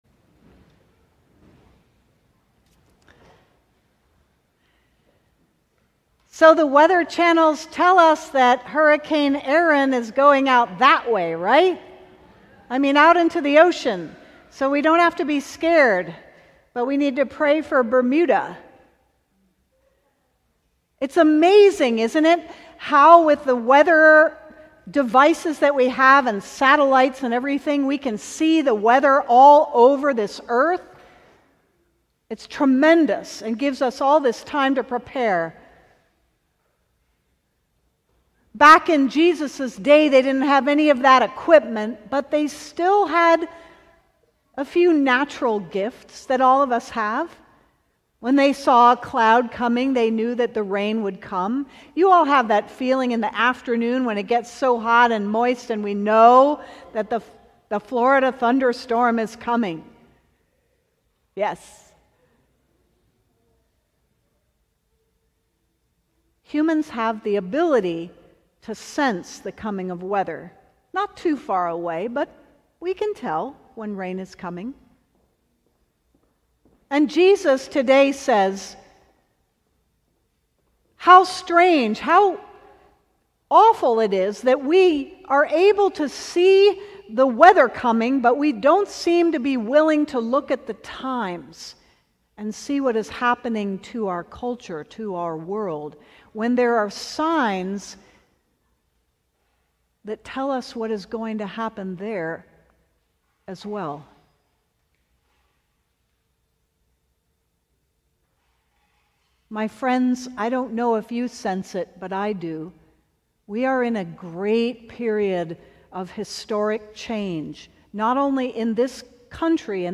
Sermon:Taming Jesus